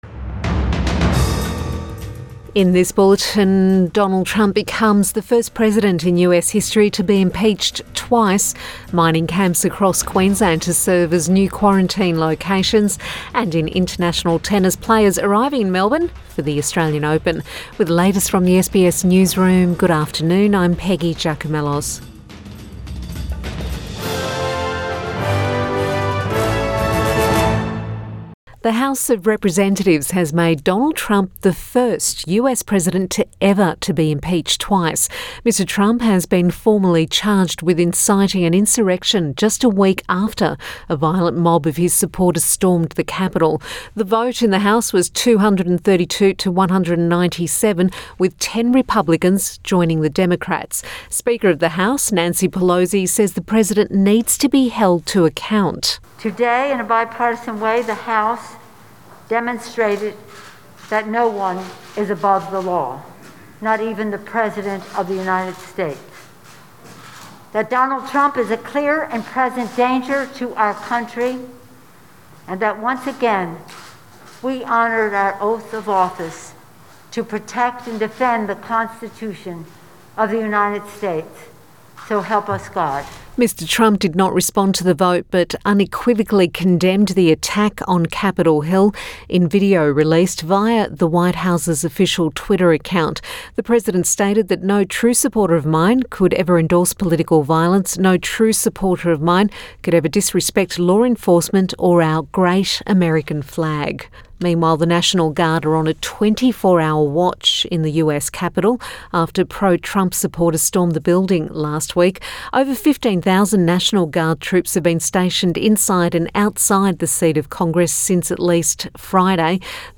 Midday bulletin 14 January 2021